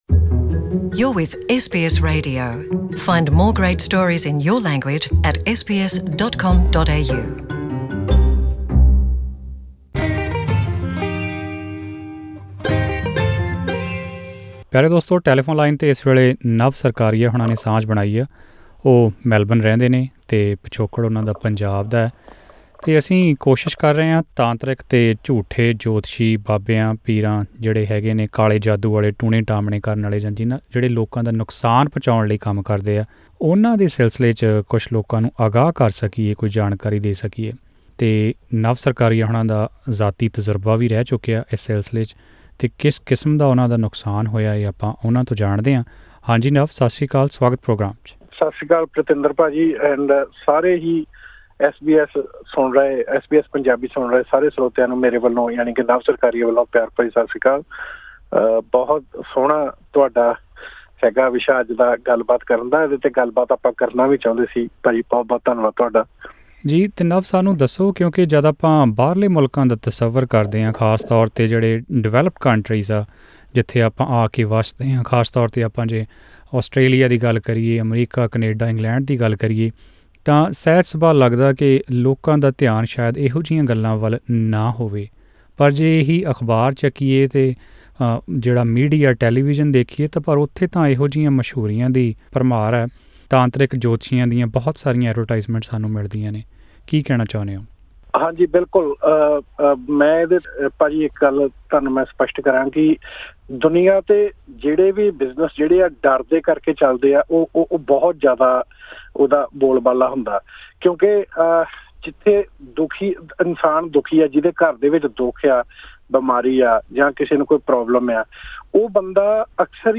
Melbourne man shares his real-life experiences of dealing with ‘fake’ babas and faith-healers